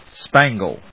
音節span・gle 発音記号・読み方
/spˈæŋgl(米国英語)/